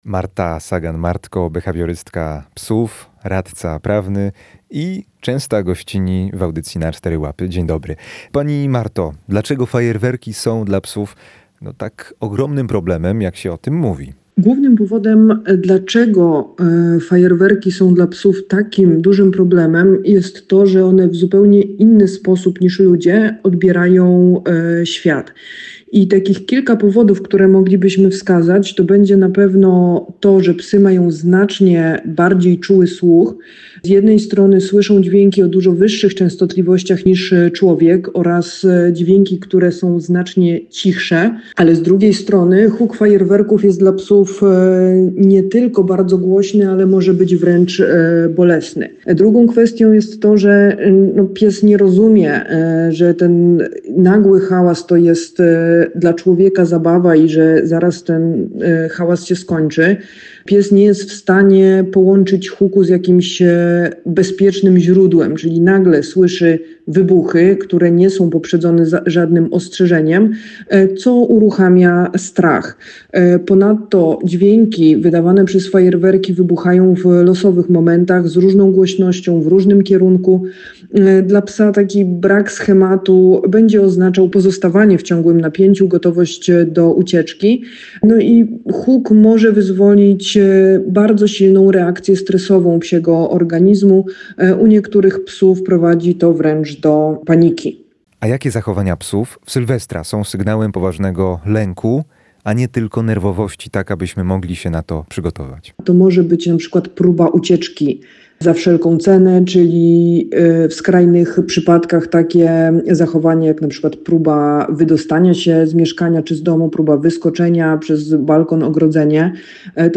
Huk fajerwerków to dla wielu zwierząt realne zagrożenie zdrowia i życia. Sylwester oznacza panikę, urazy i długotrwały stres u psów, kotów, królików i zwierząt dzikich – alarmowały ekspertki w audycji „Na cztery łapy”.